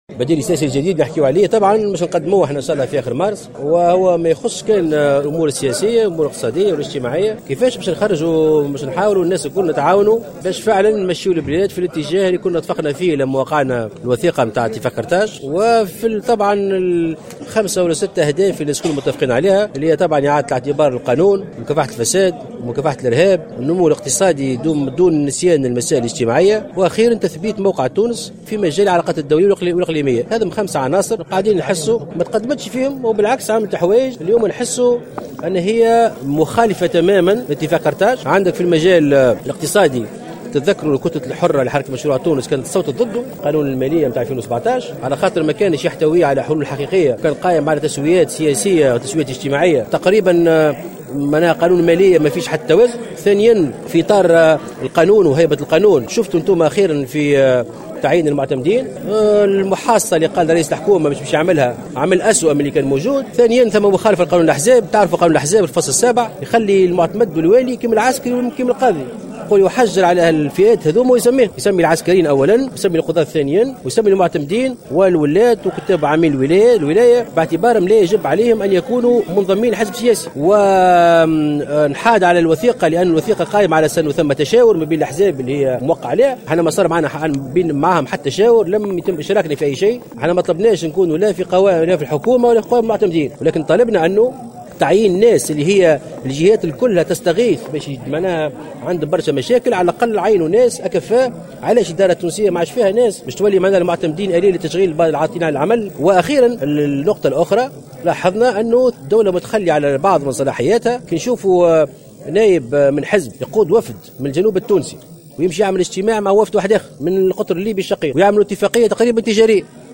أكد محسن مرزوق الامين العام لحركة مشروع تونس على هامش اشرافه على المجلس الجهوي الموسع للحركة في المنستير أن موفى شهر مارس القادم سيكون موعدا للإعلان عن البديل السياسي الجديد الذي لايهم الأمور السياسية فقط بل يهم الجانب الاقتصادي والاجتماعي.